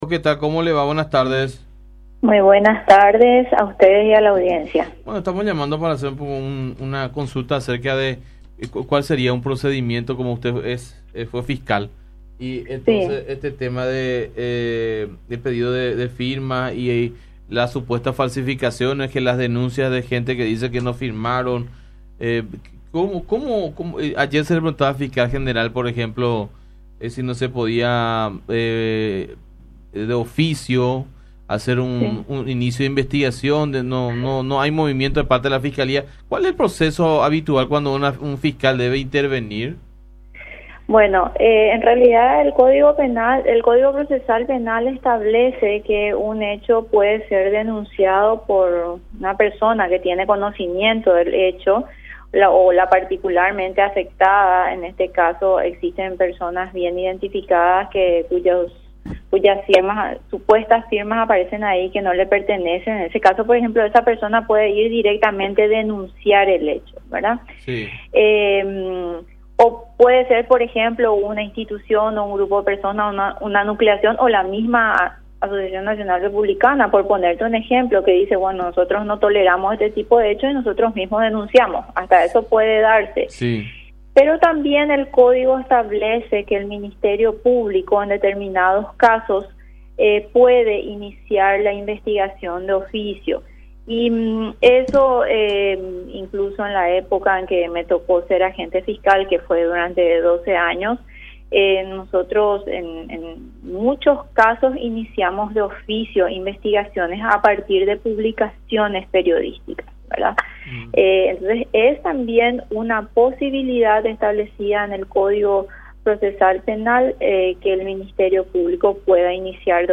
La doctora y exfiscala Rocío Vallejos habló, en contacto con La Unión R800 AM, con respecto a la falsificación de firmas. Explicó que el Código Procesal Penal establece que un ciudadano puede denunciar el hecho en caso de que se vea afectada personalmente.